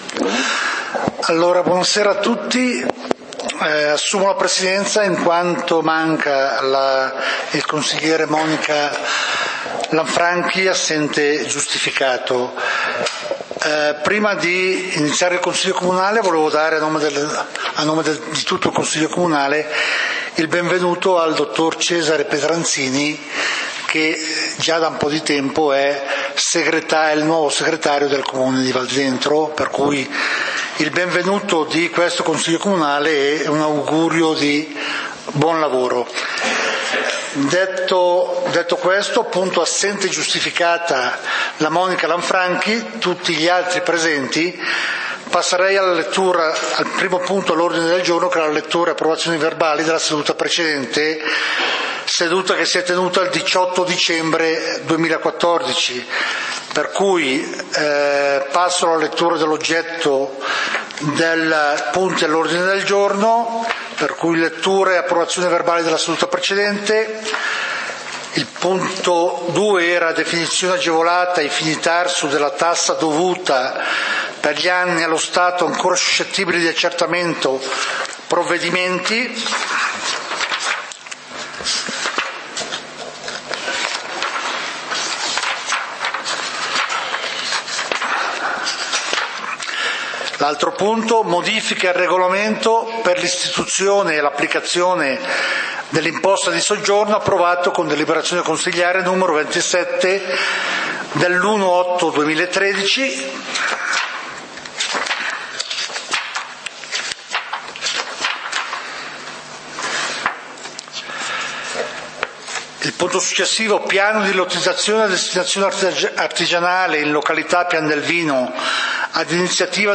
Consiglio comunale di Valdidentro del 23 Aprile 2015